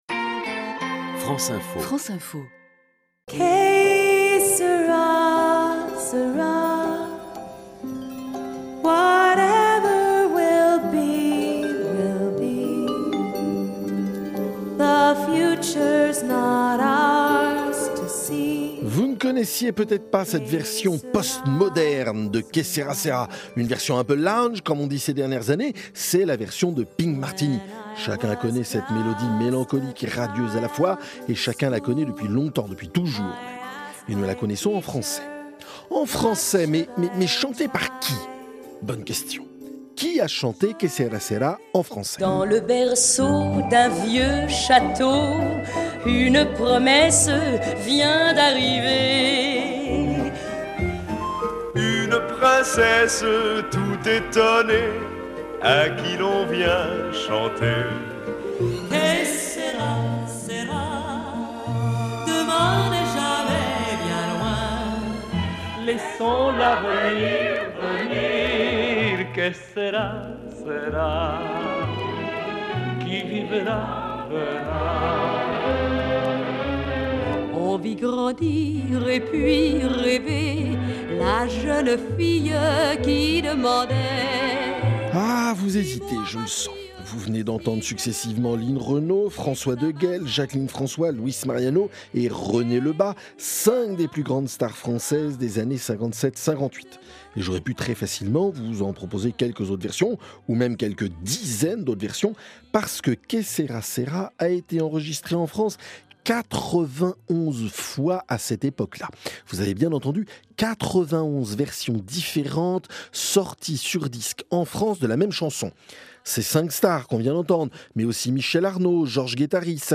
diffusées sur France Info